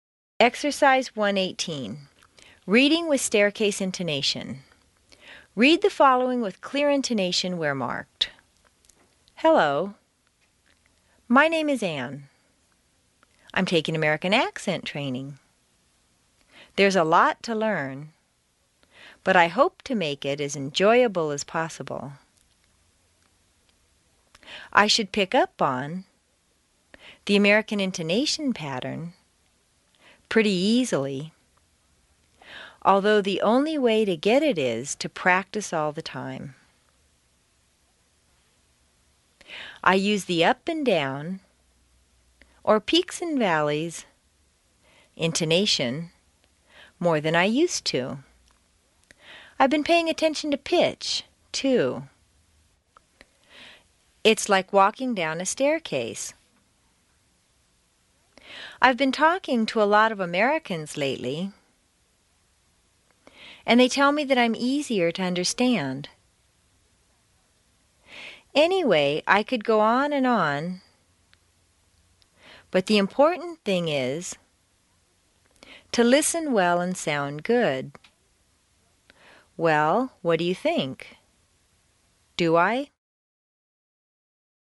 Exercise 1-18: Reading with Staircase Intonation CD 1
Read the following with clear intonation where marked.